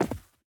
Minecraft Version Minecraft Version 1.21.5 Latest Release | Latest Snapshot 1.21.5 / assets / minecraft / sounds / mob / armadillo / unroll_finish1.ogg Compare With Compare With Latest Release | Latest Snapshot
unroll_finish1.ogg